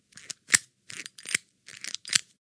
机械橡胶
描述：金属切割设备，切割橡胶状物质。
Tag: 切割 橡胶